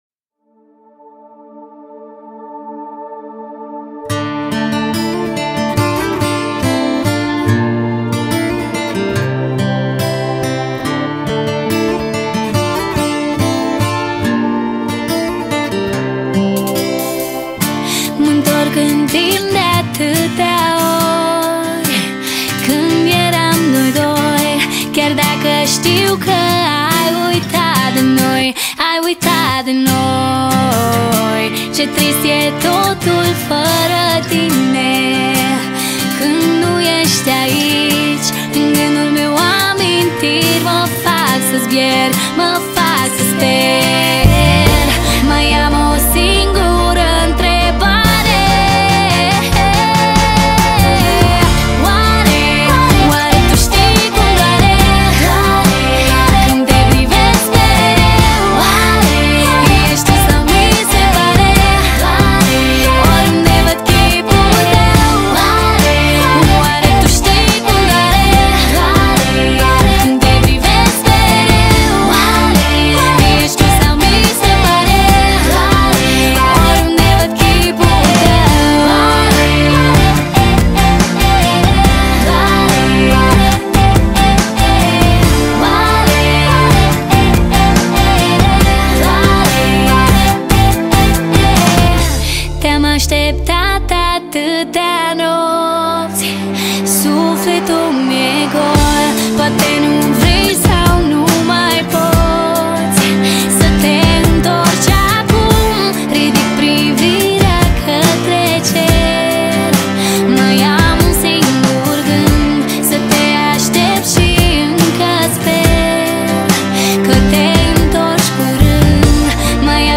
Estilo: Hip-Hop